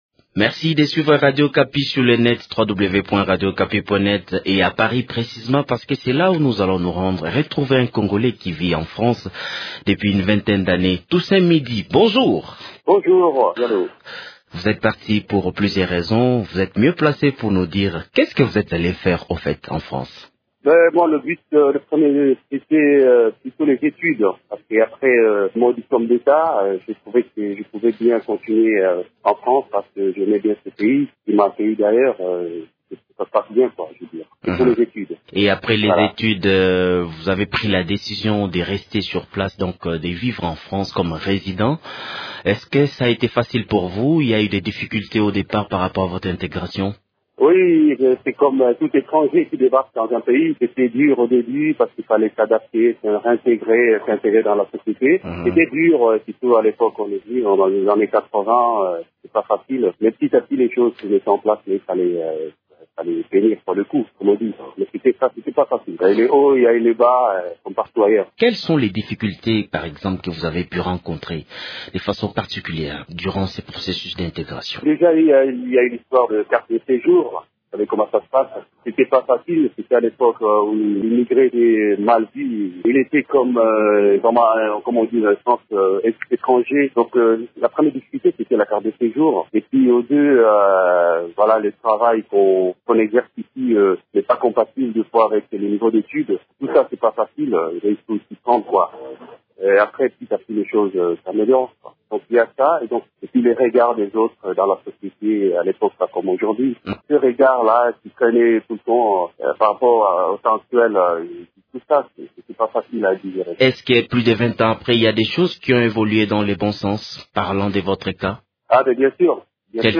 Il s’entretient au téléphone depuis Paris